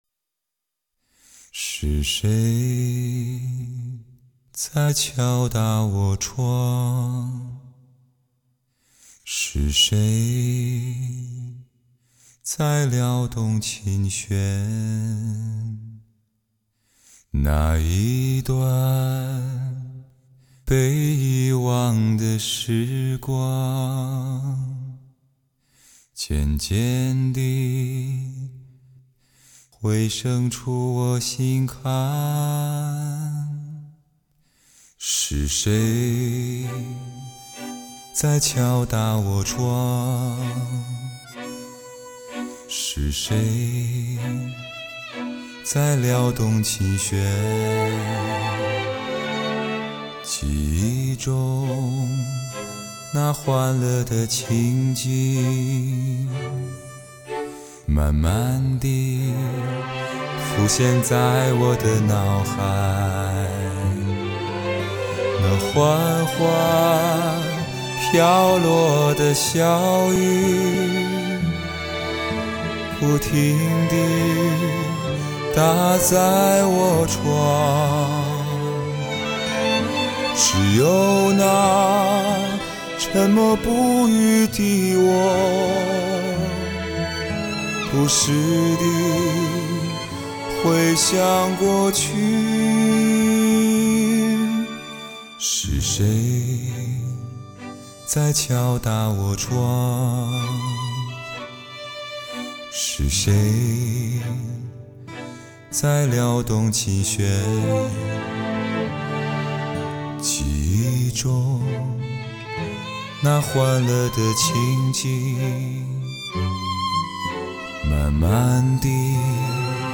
[分享]来听人声低音炮！----之二